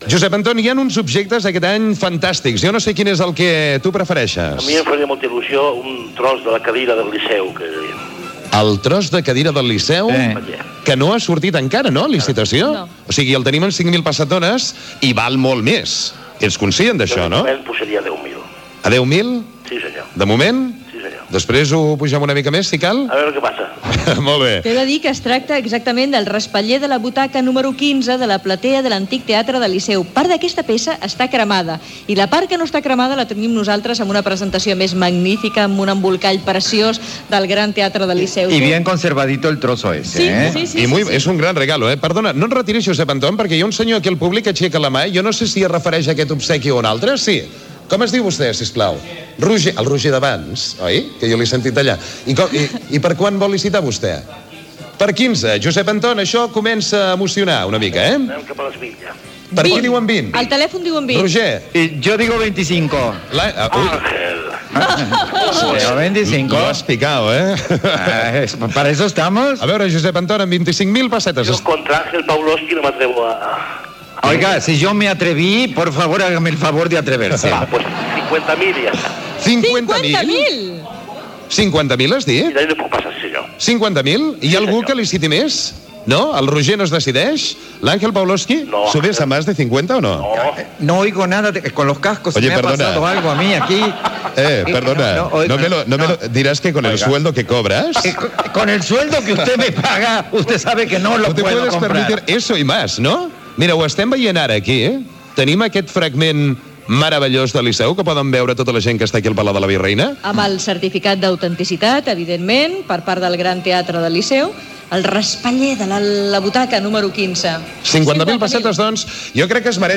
Edició del programa feta des del Palau de la Virreina de Barcelona.